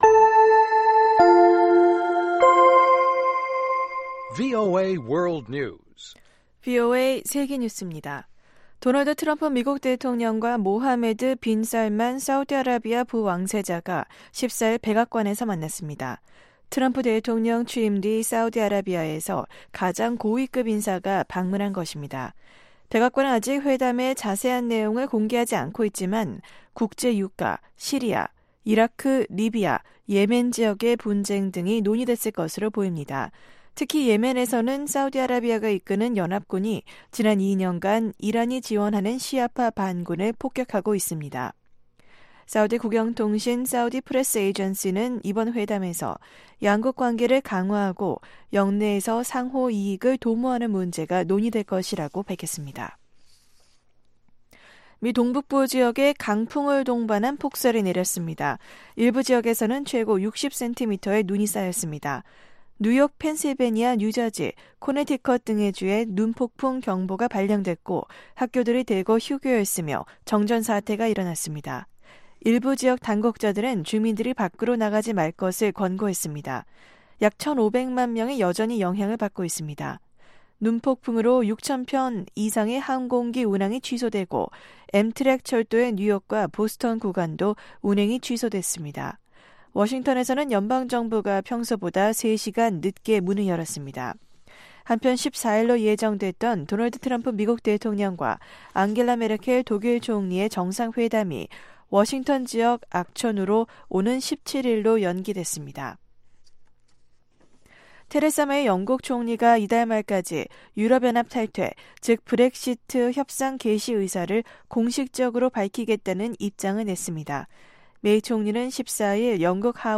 VOA 한국어 방송의 아침 뉴스 프로그램 입니다.